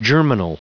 Prononciation du mot germinal en anglais (fichier audio)
Prononciation du mot : germinal